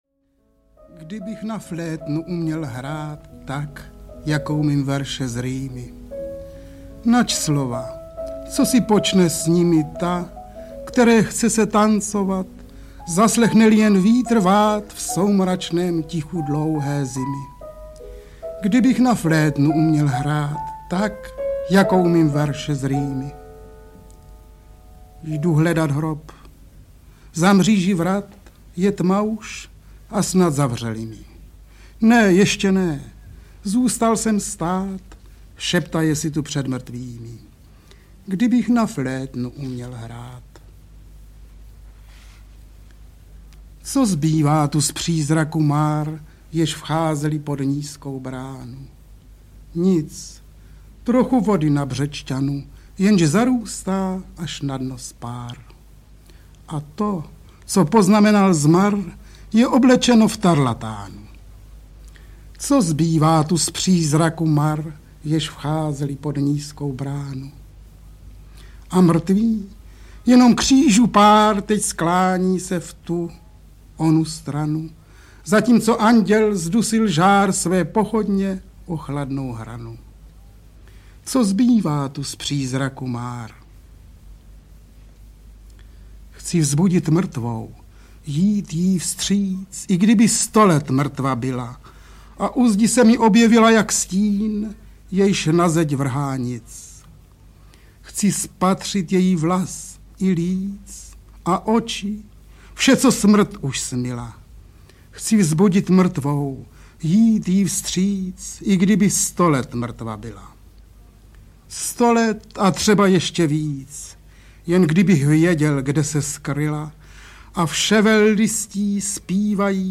Mozart v Praze, Krásná láska audiokniha
Původní titul DM 15053 "Mozart v Praze, Krásná láska" s verši Jaroslava Seiferta a Františka Branislava v interpretaci samotných autorů byl vydán v Supraphonu v roce 1957 - nyní vychází poprvé digitálně.
Ukázka z knihy
• InterpretFrantišek Branislav, Jaroslav Seifert